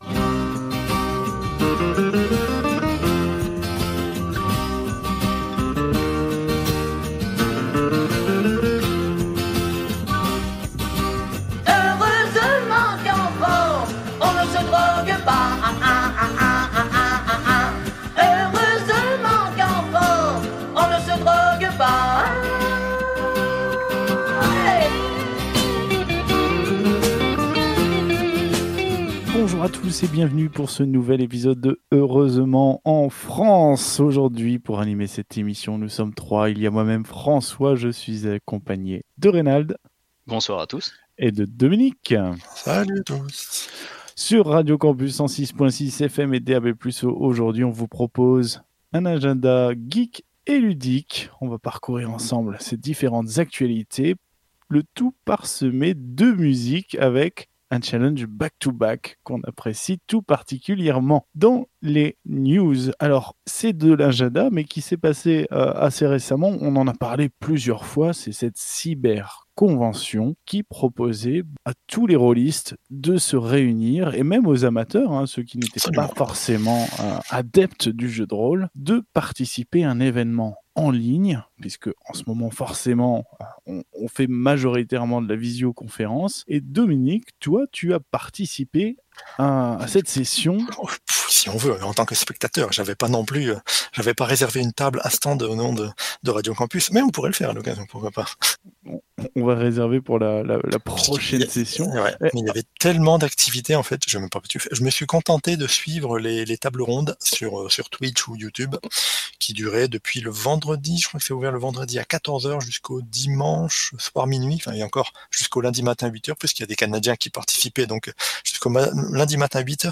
Au sommaire de cet épisode diffusé le 6 décembre 2020 sur Radio Campus 106.6 :